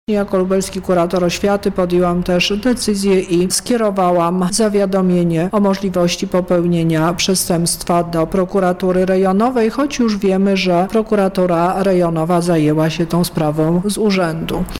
nauczyciele – dodaje Misiuk